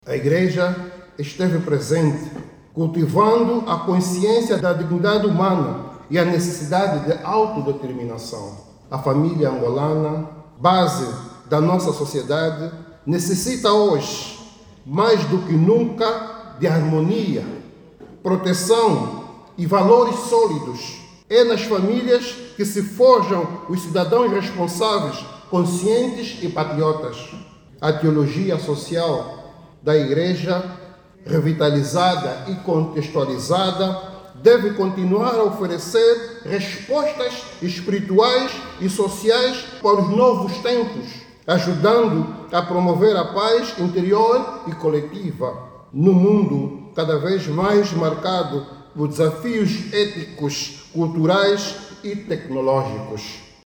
O director nacional dos Assuntos Religiosos, Osvaldo Paixão, reconheceu o contributo das igrejas na pacificação dos espíritos e na preservação da independência nacional.